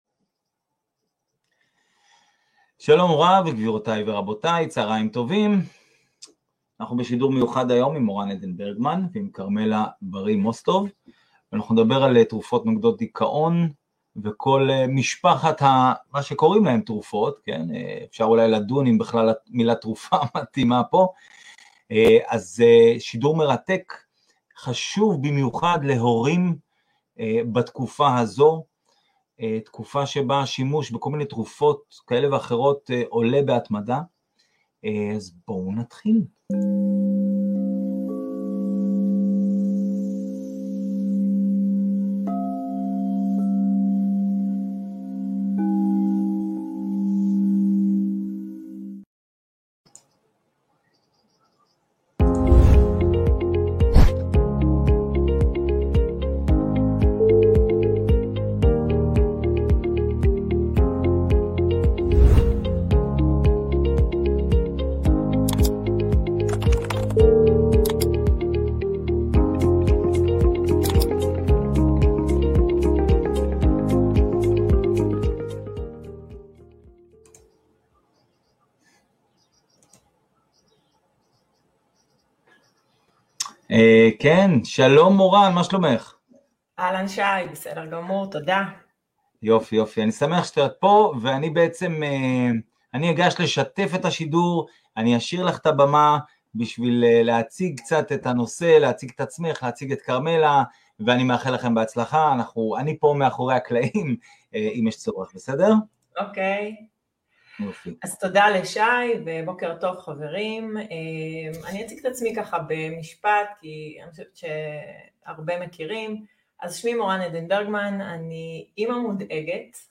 בשיחה מרתקת